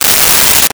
Tear Paper 11
Tear Paper 11.wav